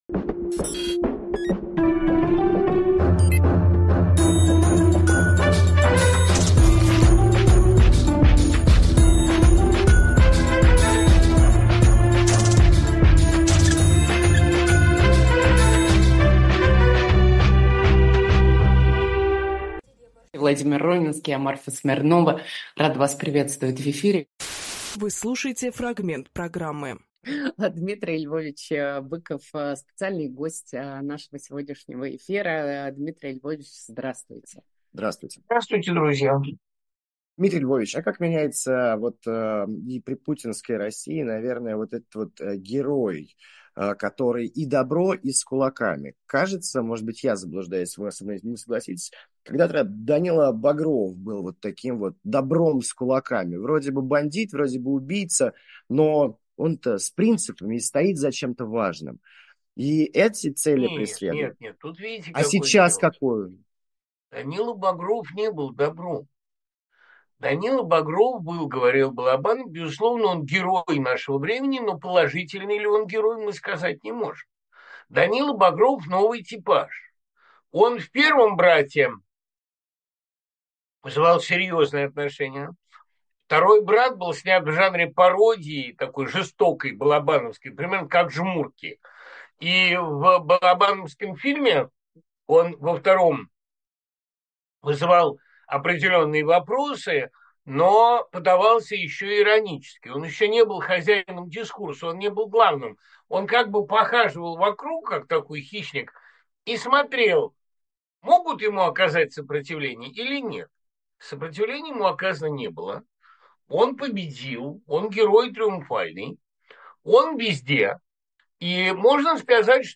Фрагмент эфира от 21.01